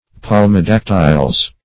Search Result for " palmidactyles" : The Collaborative International Dictionary of English v.0.48: Palmidactyles \Pal`mi*dac"ty*les\, n. pl.